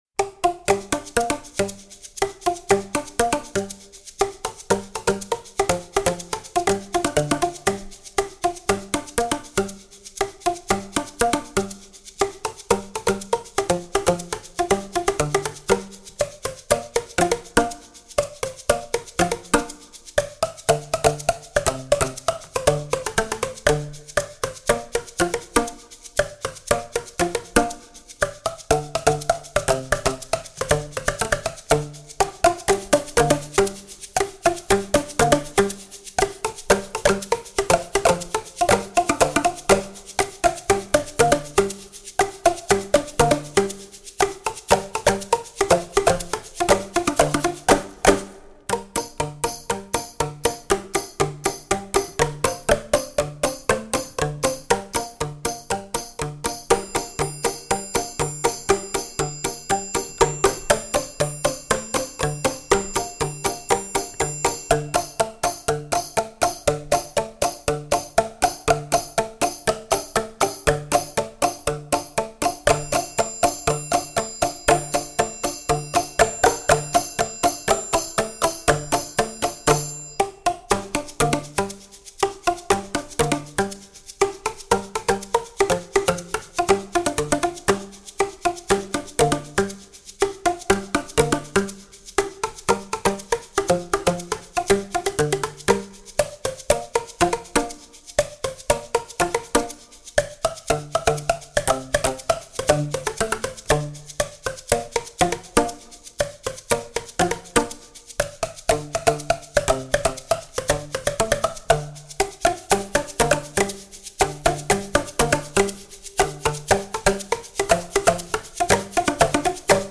Voicing: 8-10 Percussion